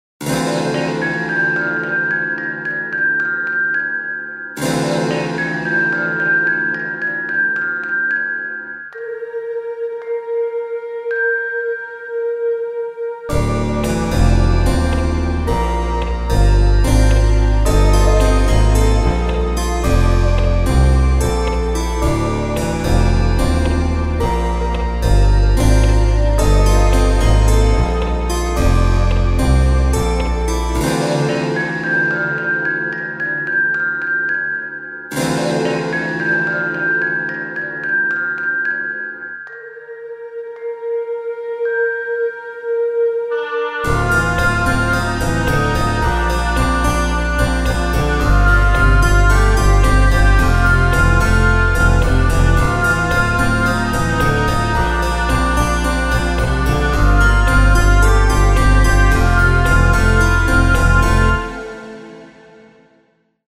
フリーBGM ダンジョン
不気味 冷たい 暗い